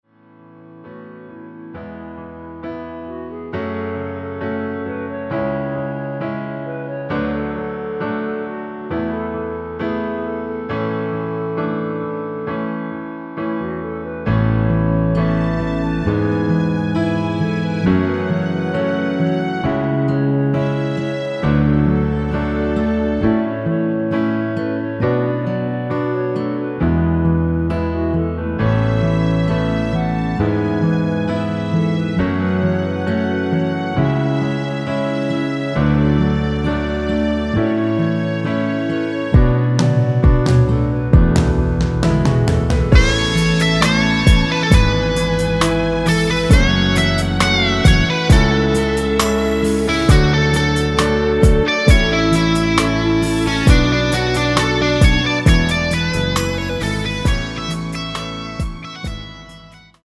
1절후 후렴으로 편곡한 MR (진행 순서는 아래의 가사와 미리듣기 참조 하세요)
엔딩이 페이드 아웃이라 노래 하시기 좋게 엔딩을 만들어 놓았습니다.
◈ 곡명 옆 (-1)은 반음 내림, (+1)은 반음 올림 입니다.
멜로디 MR이라고 합니다.
앞부분30초, 뒷부분30초씩 편집해서 올려 드리고 있습니다.
중간에 음이 끈어지고 다시 나오는 이유는